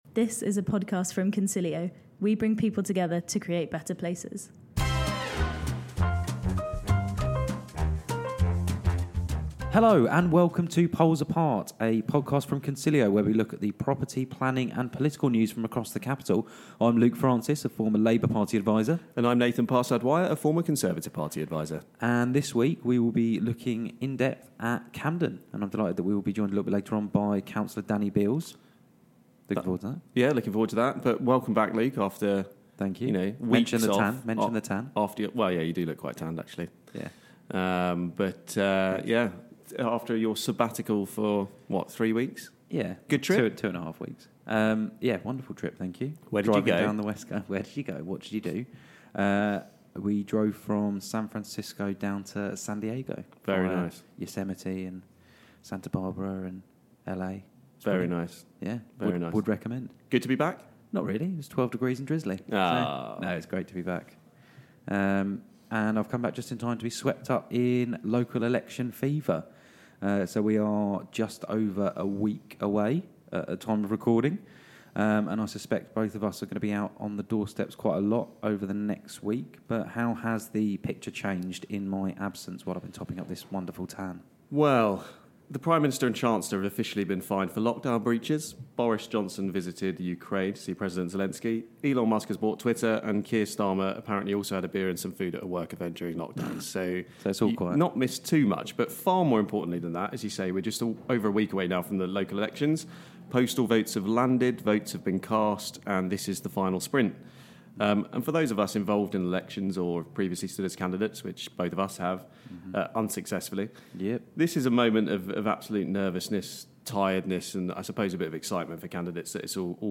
The pair are joined by Cllr Danny Beales, Cabinet Member for Investing in Communities, Culture and an Inclusive Economy in Camden for an extended interview.